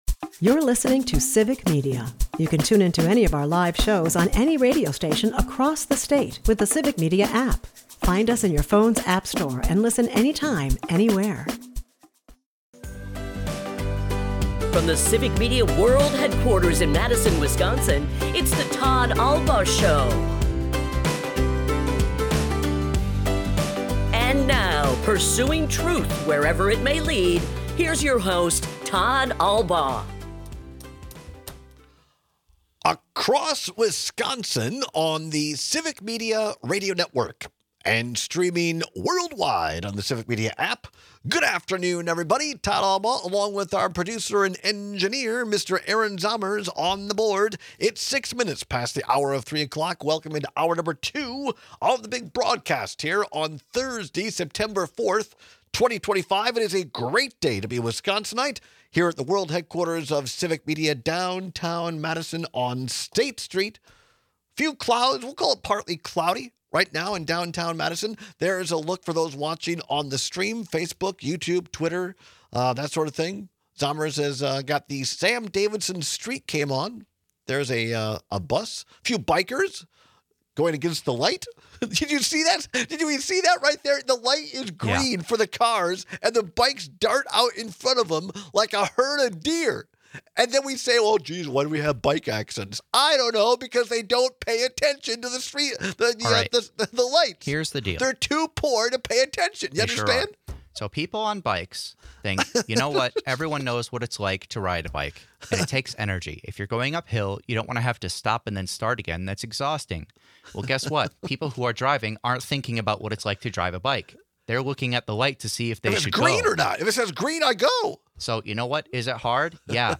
We take some surprising calls and texts on when each should be used.&nbsp